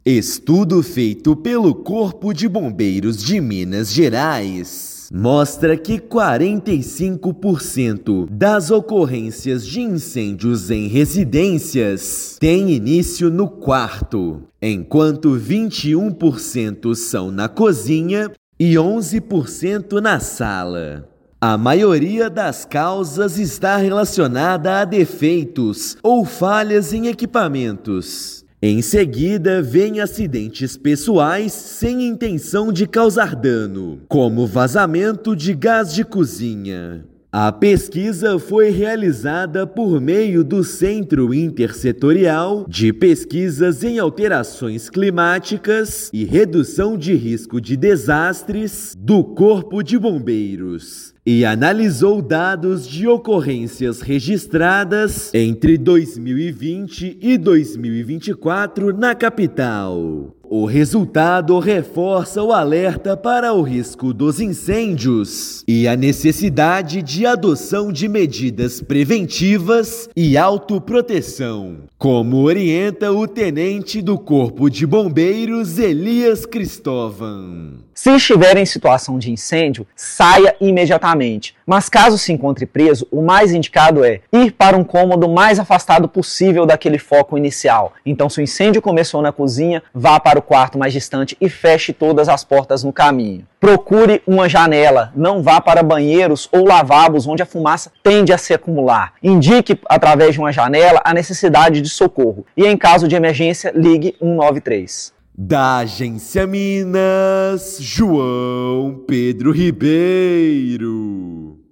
Levantamento aponta que 64% dos incêndios ocorrem em residências e a maioria tem início em quartos, com causas ligadas a falhas em equipamentos. Ouça matéria de rádio.